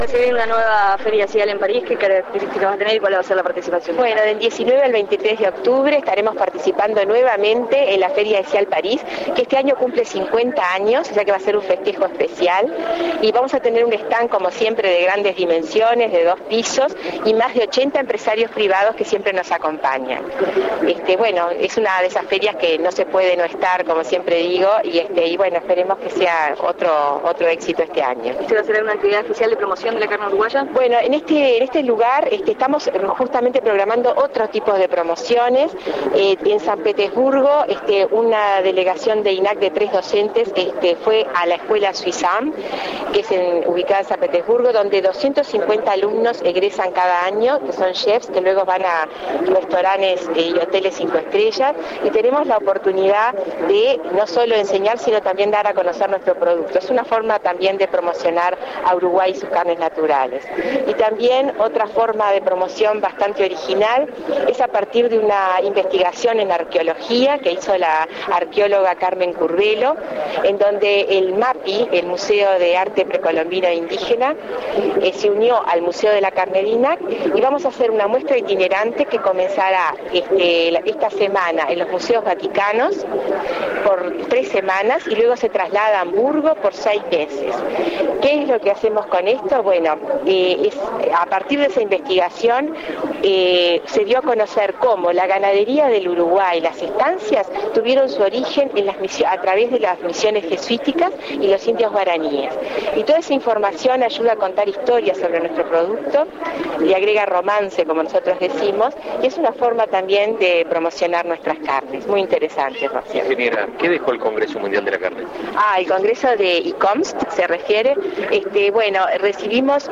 en ronda de prensa en el Stand del Ministerio de Turimo y Deporte de Expo Prado